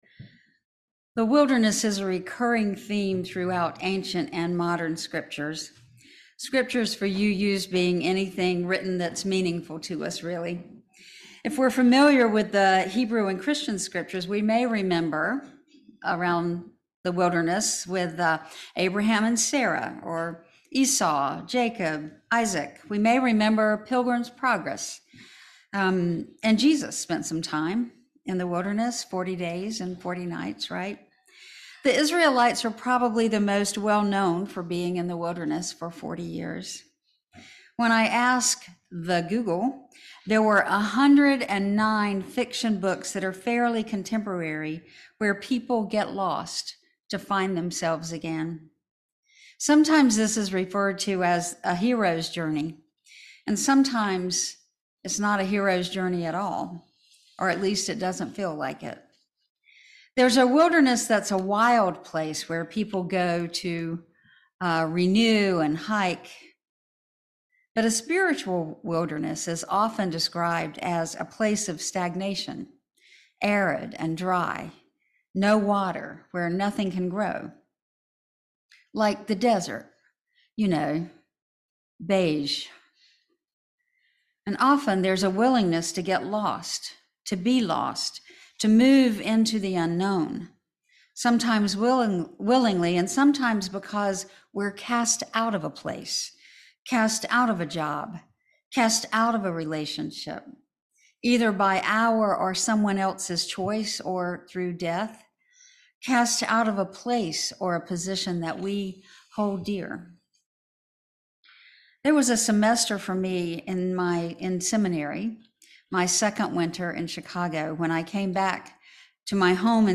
This sermon explores the spiritual wilderness as a transitional space defined by feeling lost, stagnant, or overwhelmed by life’s hardships